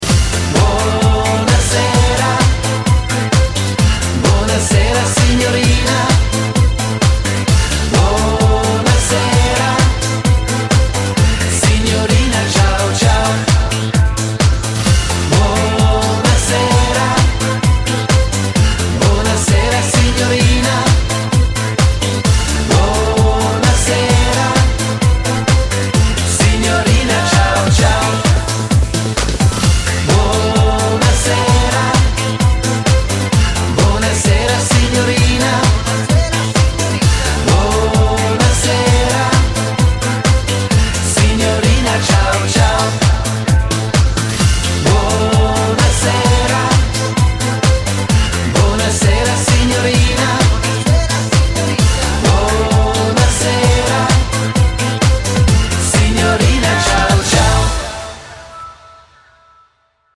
• Качество: 128, Stereo
Испанский клубняк, а мы то с вами знаем, что они заводные!